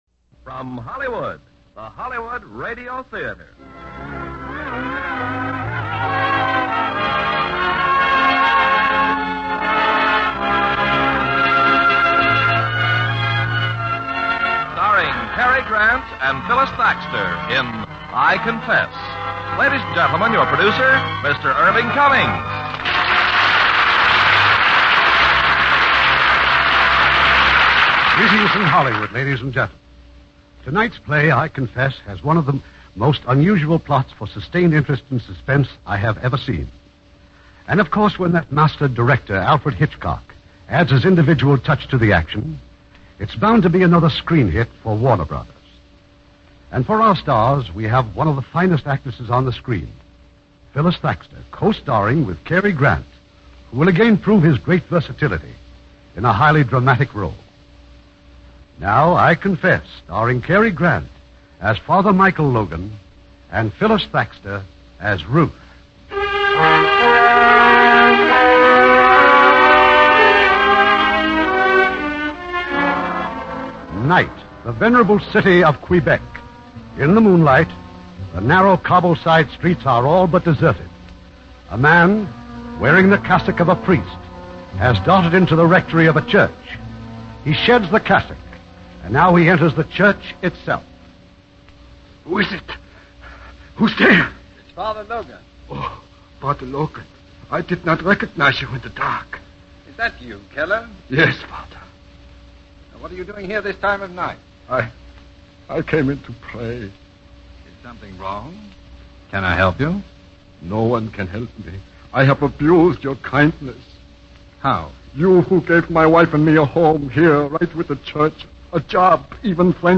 Lux Radio Theater Radio Show
I Confess, starring Cary Grant, Phyllis Thaxter